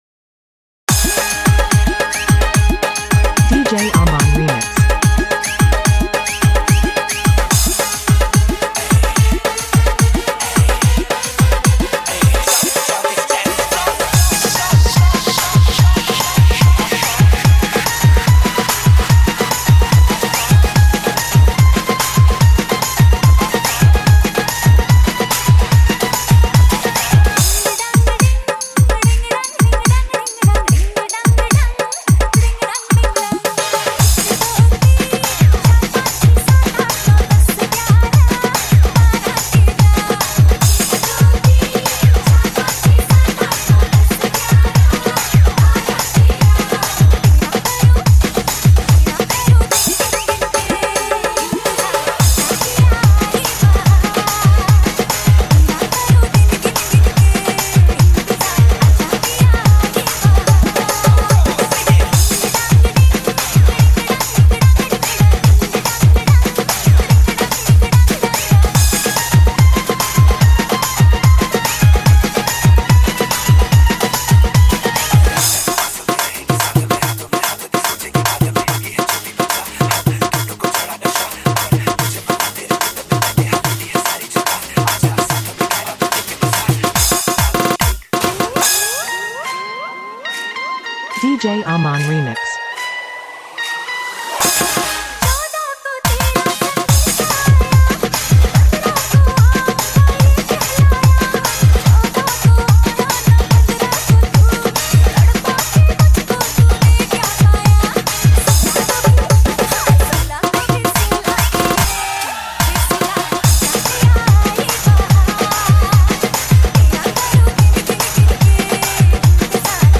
High quality Sri Lankan remix MP3 (3.5).